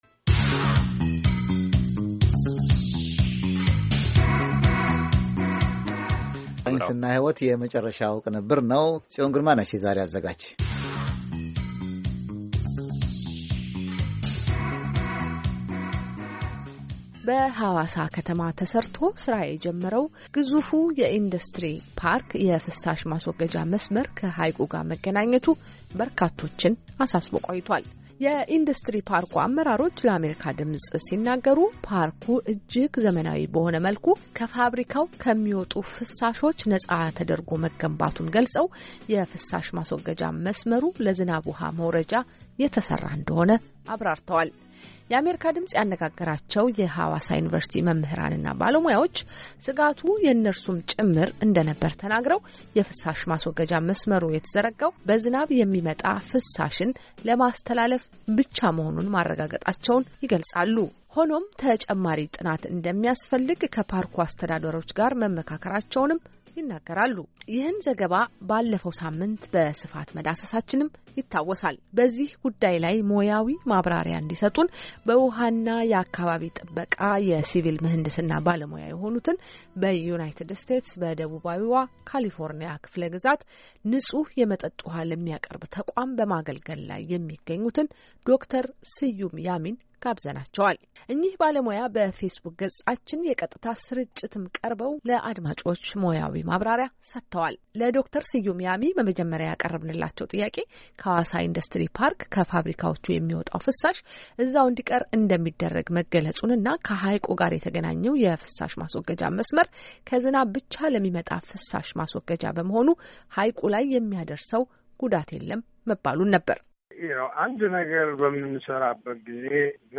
እኚህ ባለሞያም በፌስቡክ ገፃችን የቀጥታ ስርጭት ቀርበው ሞያዊ ማብራሪያ ሰተወ ነበር።